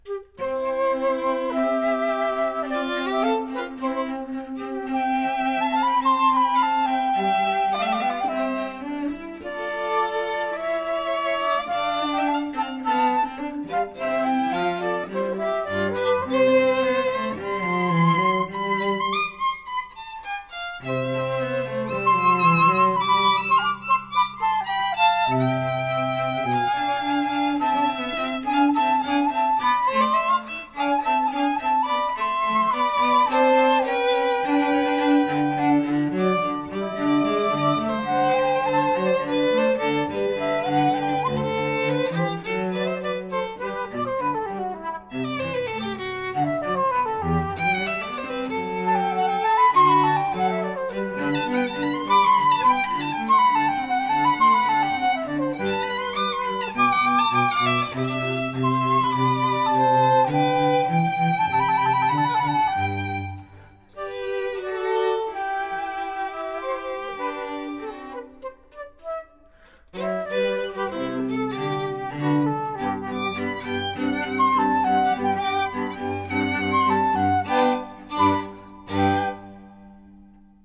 The Times Trio is an elegant, classical trio of flute, violin and cello.